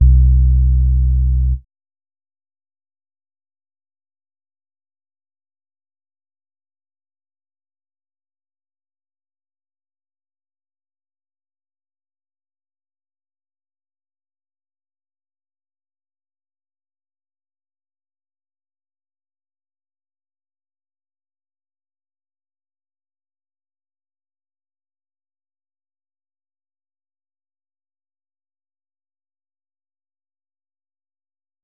808s / 911 Bass